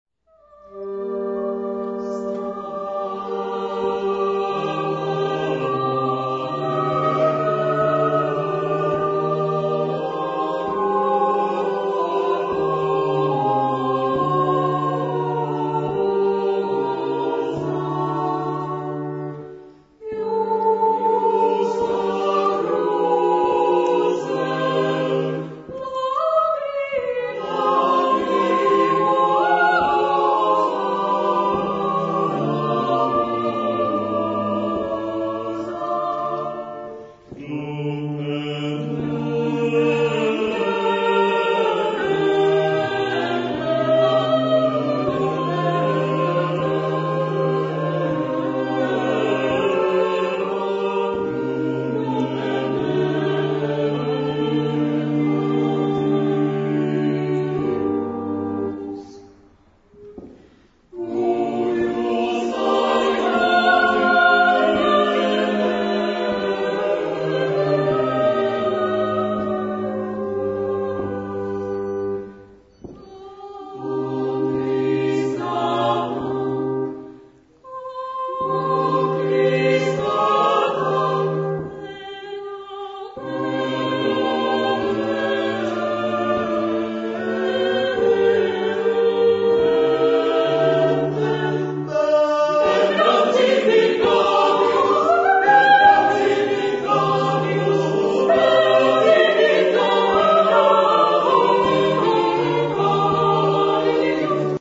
Zde jsou uvedeny ukázky skladeb, které zazněli na koncertě.